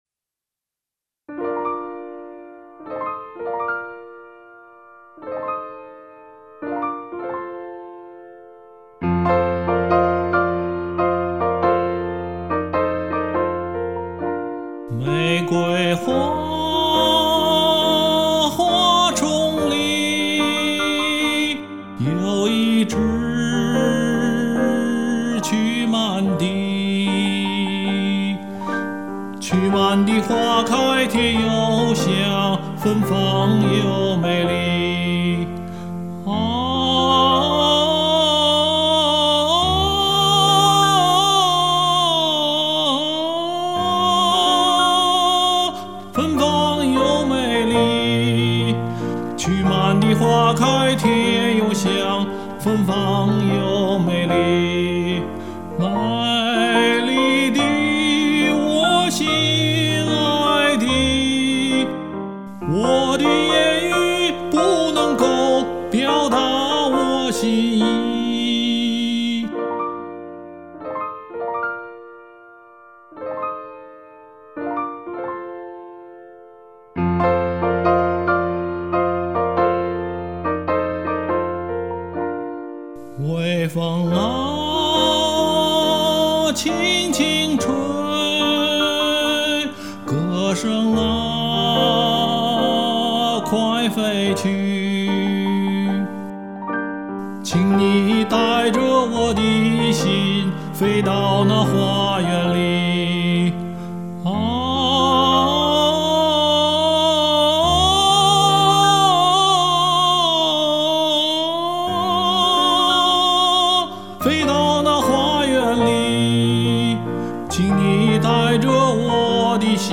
唱的不好，总比没有强啊，呵呵。
2.曲蔓地(新疆民歌)